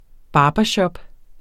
Udtale [ ˈbɑːbʌˌɕʌb ]